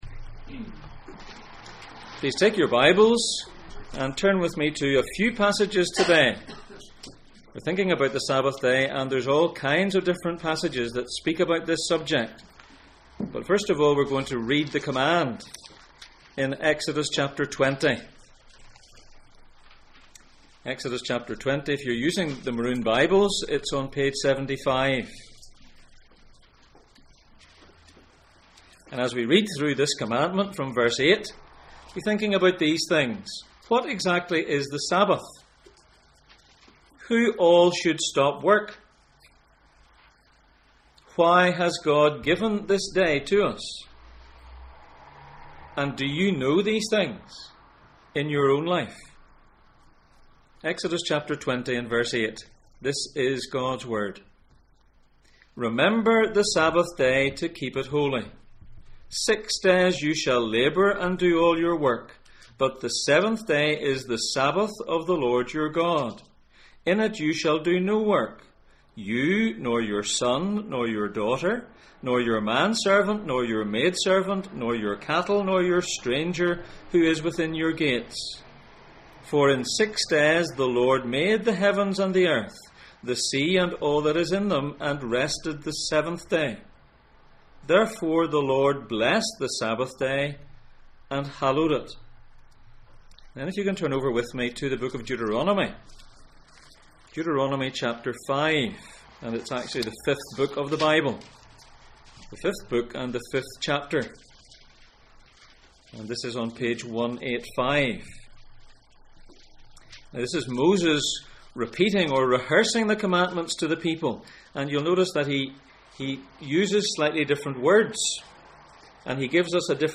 Gods instructions for life Passage: Exodus 20:8-11, Deuteronomy 5:12-15, Isaiah 58:13-14, Mark 2:27-28 Service Type: Sunday Morning